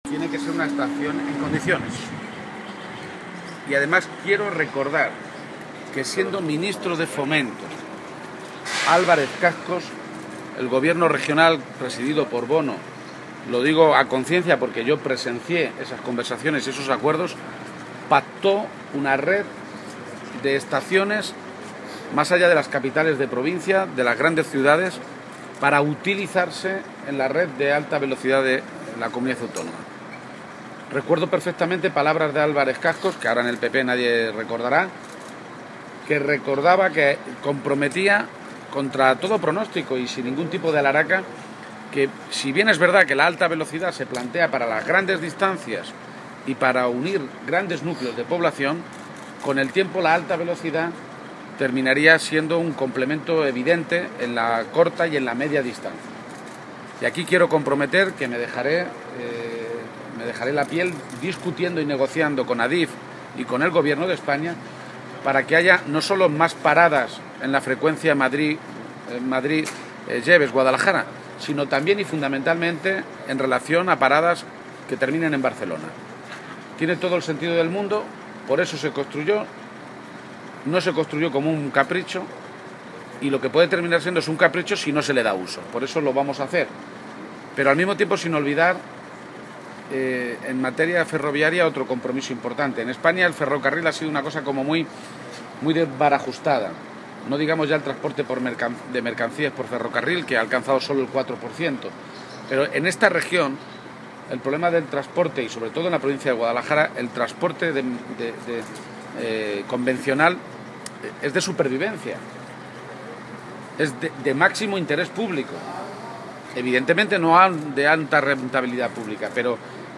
En este contexto, en declaraciones a los medios en la estación Guadalajara-Yebes, García-Page también ha asegurado que el ferrocarril en España «ha sido una cosa muy desbarajustada» y que en esta región el problema del transporte convencional es «de supervivencia» aunque, en algunos casos, «es la única alternativa», comprometiéndose a retomar este tipo de ferrocarril «en frecuencias como Jadraque o Siguenza».